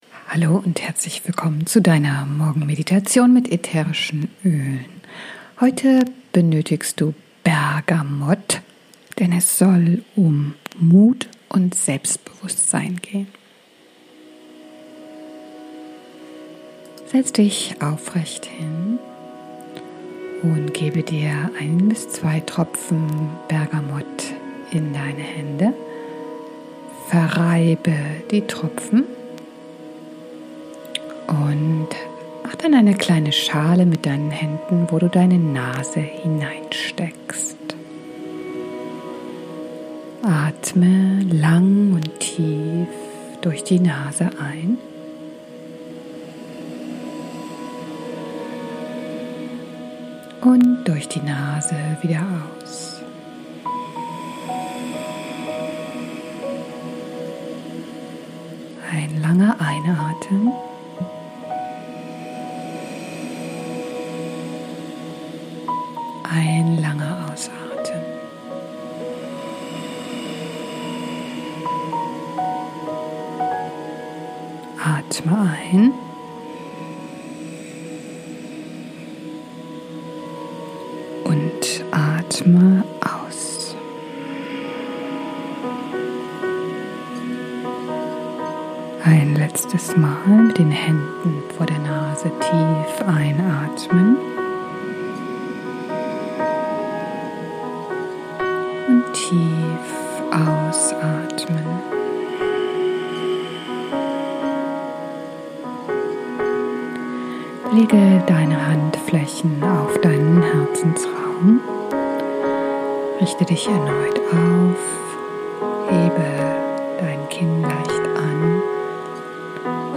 #1 Meditation - Selbstbewusst und mutig in den Tag